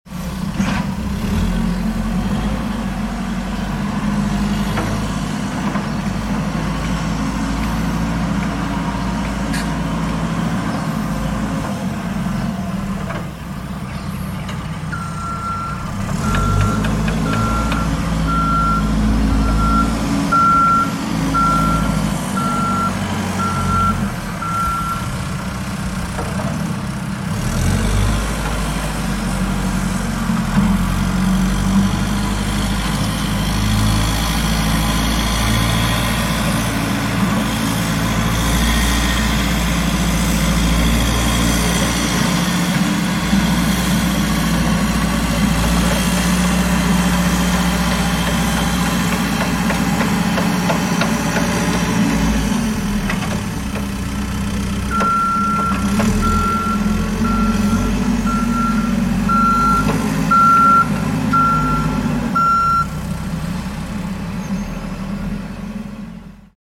Wheel Loader Working Sound FX Sound Effects Free Download